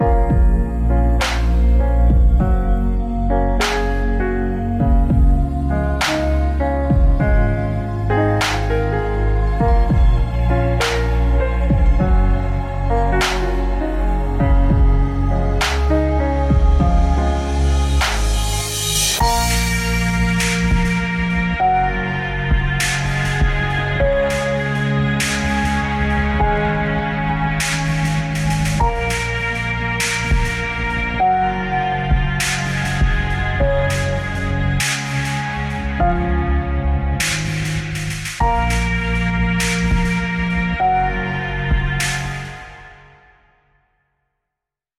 Korištena tema: beautiful, inspirational